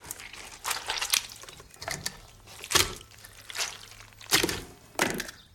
action_gut_0.ogg